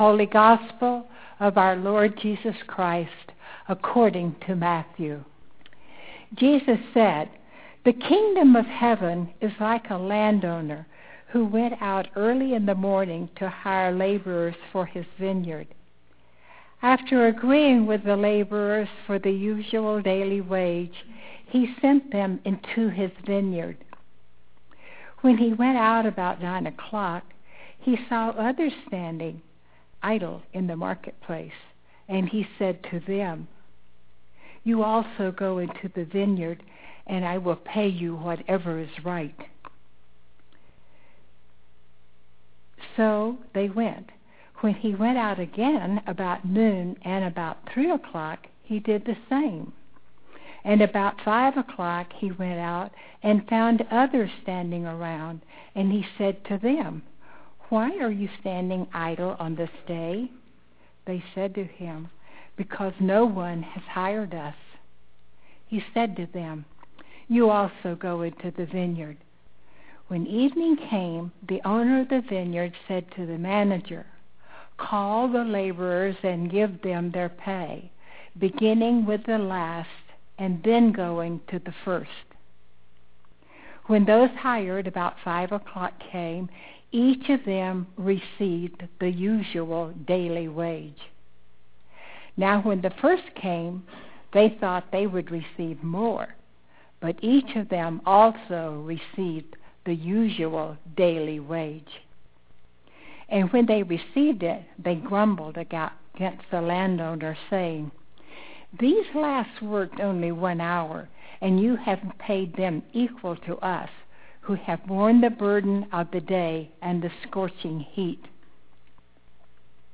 Click on the white triangle in the black bar-shaped player below to listen to the scripture and sermon now or click on the link labeled: Download below the player to save an audio mp3 file for listening later.